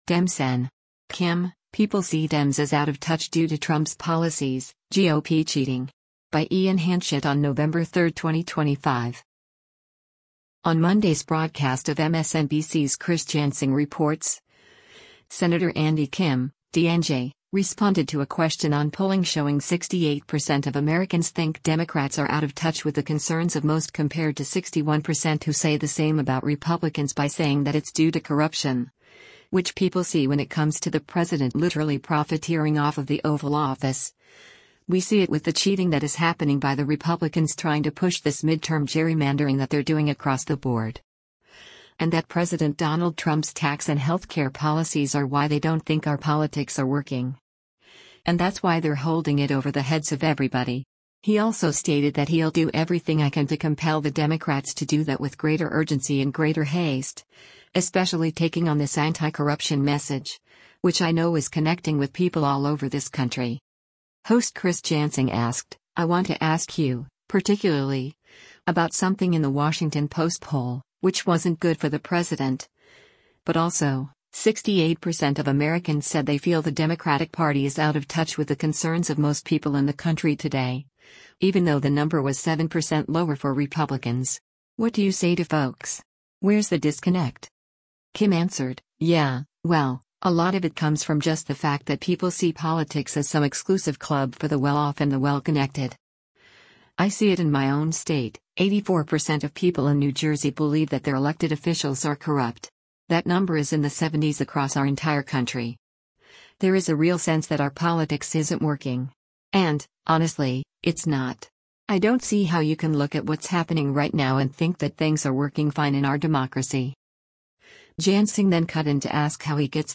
Video Source: MSNBC
Host Chris Jansing asked, “I want to ask you, particularly, about something in the Washington Post poll, which wasn’t good for the President, but also, 68% of Americans said they feel the Democratic Party is out of touch with the concerns of most people in the country today, even though the number was 7% lower for Republicans.
Jansing then cut in to ask how he gets things to work.